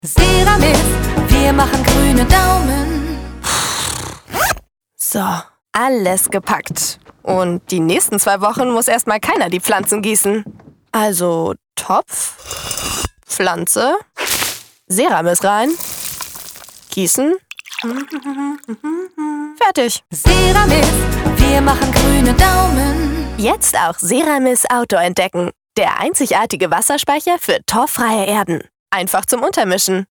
hell, fein, zart
Jung (18-30)
Eigene Sprecherkabine
Commercial (Werbung), Off, Scene